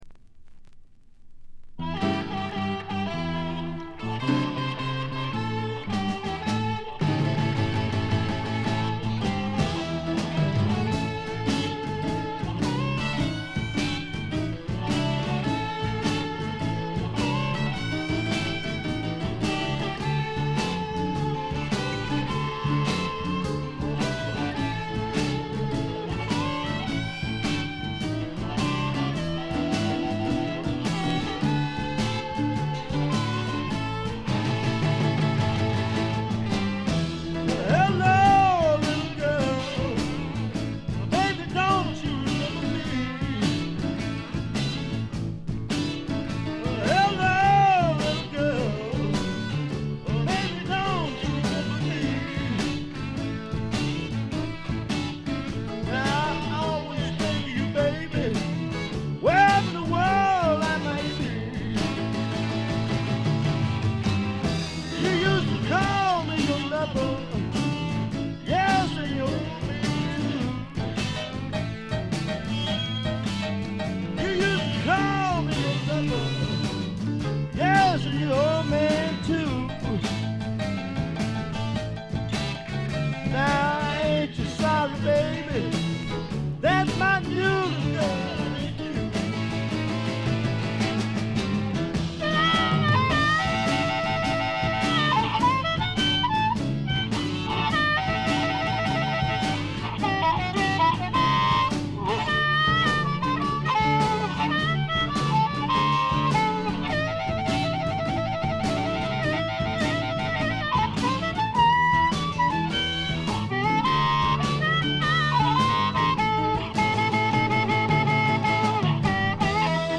69年という最良の時代の最良のブルース・ロックを聴かせます。
試聴曲は現品からの取り込み音源です。